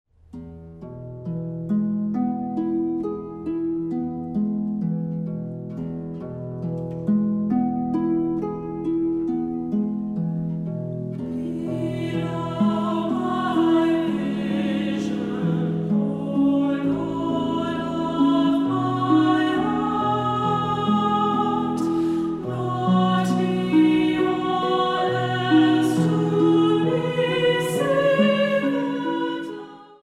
Gaelic Hymn
soprano
• Studio : Église Saint-Matthias